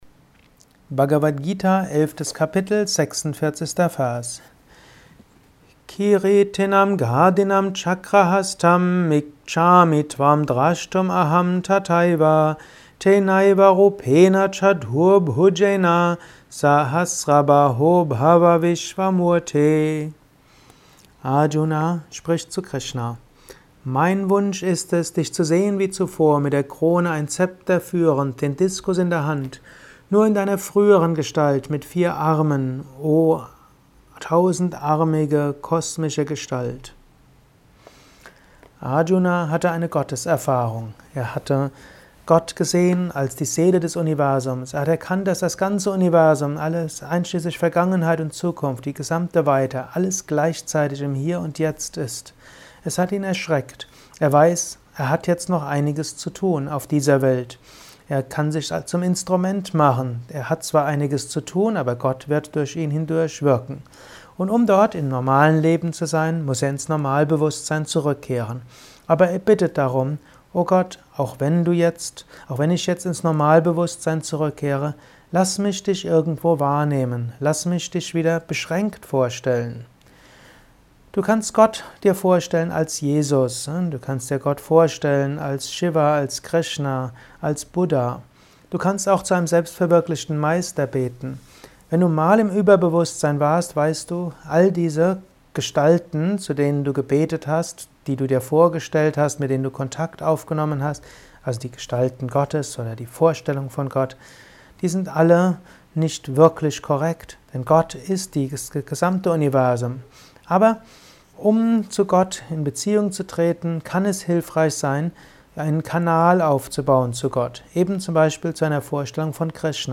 Aufnahme speziell für diesen Podcast.